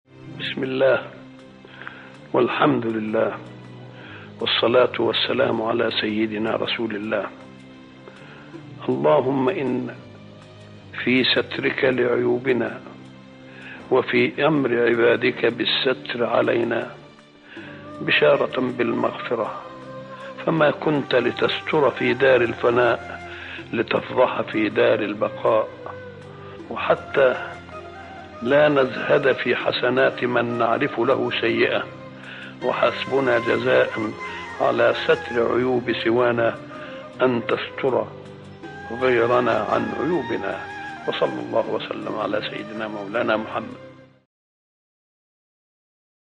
دعاء خاشع يطلب فيه العبد من الله تعالى ستر العيوب والمغفرة، معترفاً بنعمته في الإحسان وإخفاء الزلات. يعبر النص عن رجاء المؤمن بلقاء ربه وهو عنه راضٍ، ويختتم بالصلاة على النبي محمد صلى الله عليه وسلم.